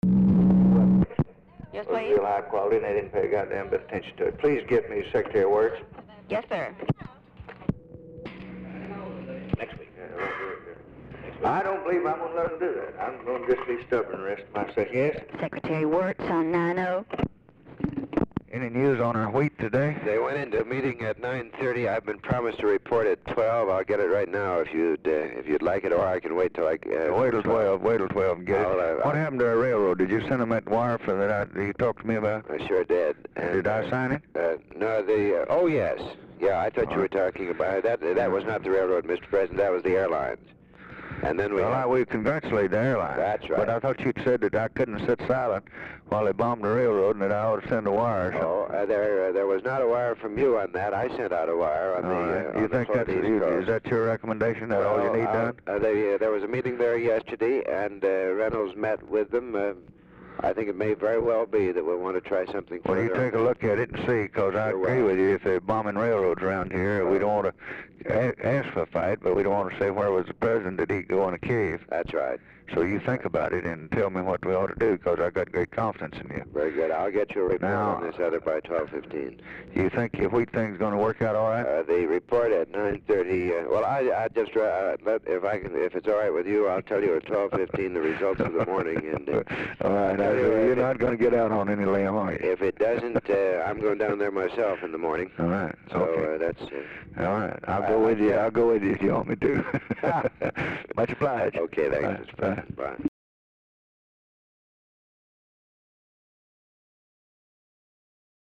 Telephone conversation # 2112, sound recording, LBJ and WILLARD WIRTZ, 2/19/1964, 11:30AM | Discover LBJ
Format Dictation belt
Location Of Speaker 1 Oval Office or unknown location